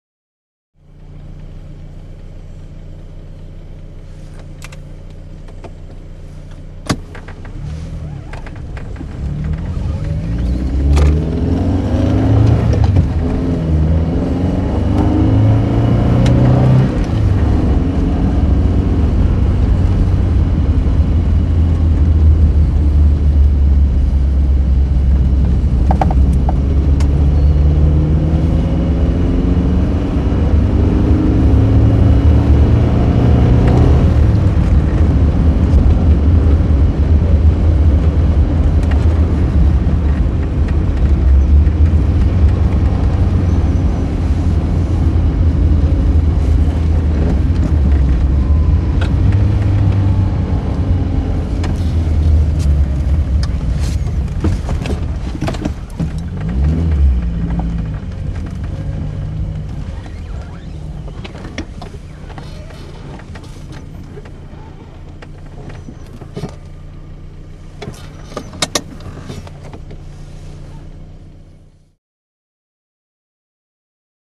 Automobile; Interior ( Constant ); Interior Audi 80l Long Idling, Then Fast Driving With Gear Work.